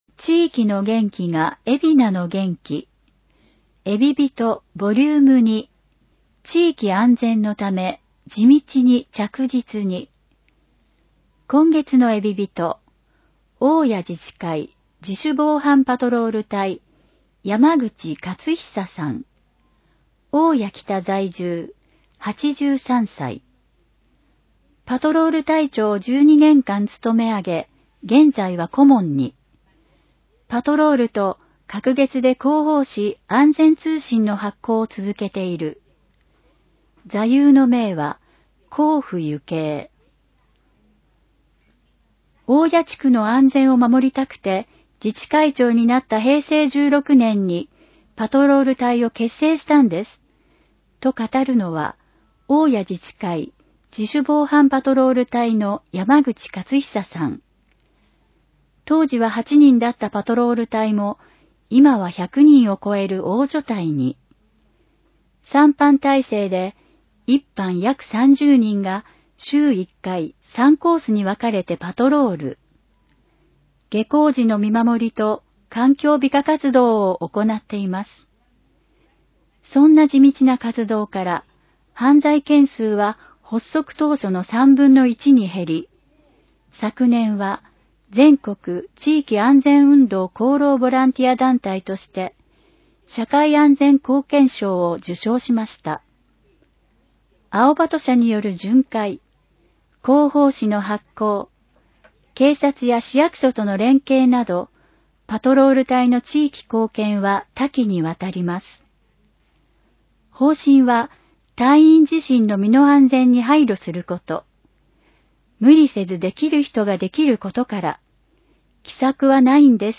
広報えびな 平成30年2月15日号（電子ブック） （外部リンク） PDF・音声版 ※音声版は、音声訳ボランティア「矢ぐるまの会」の協力により、同会が視覚障がい者の方のために作成したものを登載しています。